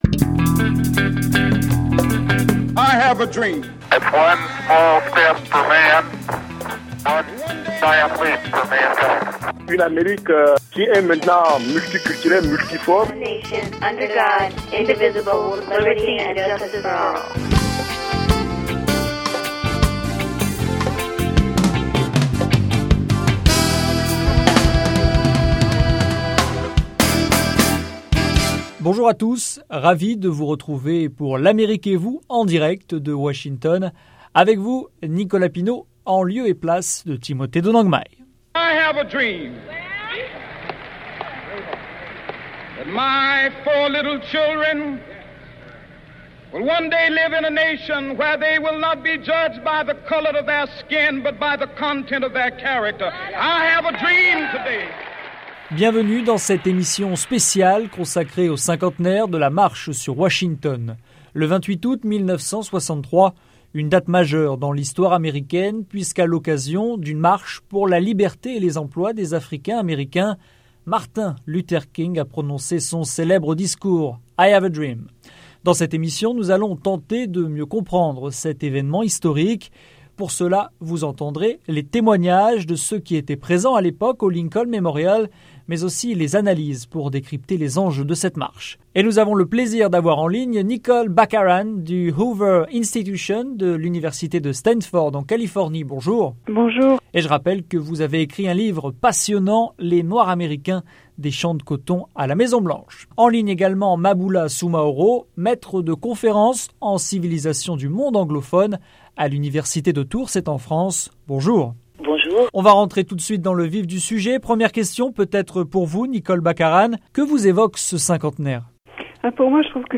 Le rêve de MLK : émission spéciale, cinquante ans après